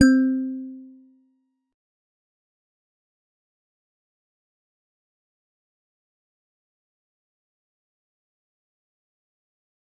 G_Musicbox-C4-mf.wav